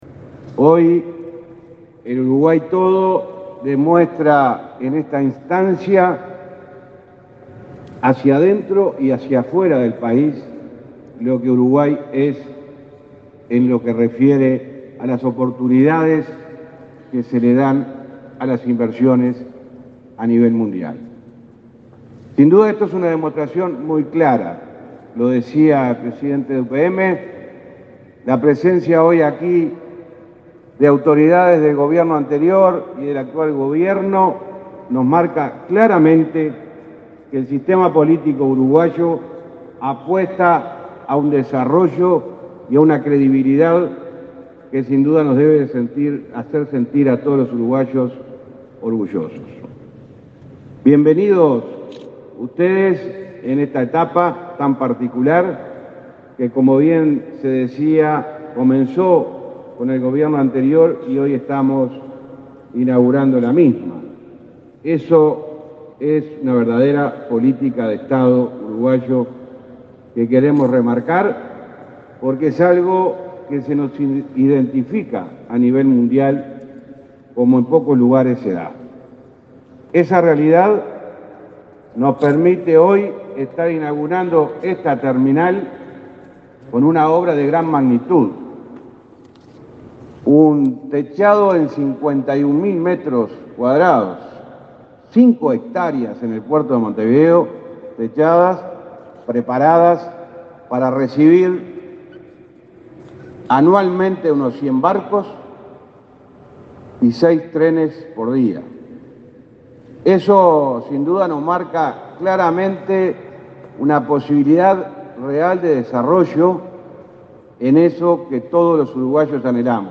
Palabras del ministro de Transporte, José Luis Falero
El ministro de Transporte, José Luis Falero, participó en el acto de inauguración de la terminal de UPM en el puerto de Montevideo.